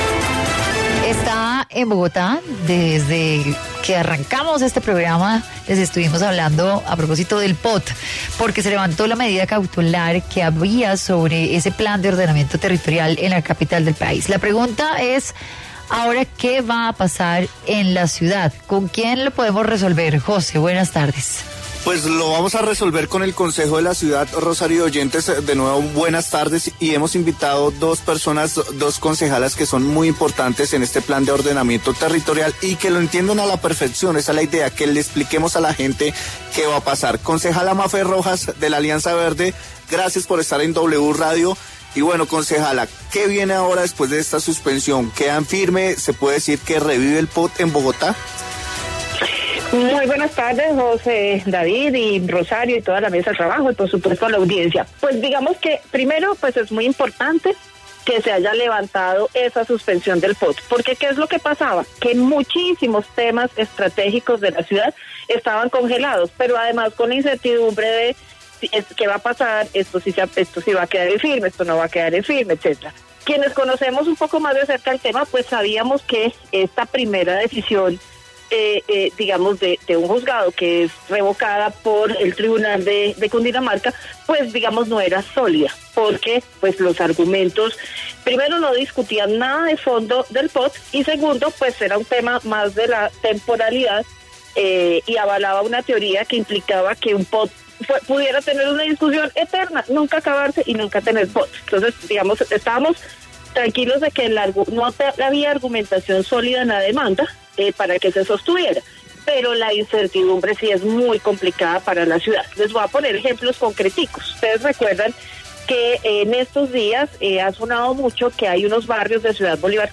Las concejalas Diana Diago y ‘Mafe’ Rojas explicaron cuáles son las implicaciones de esta decisión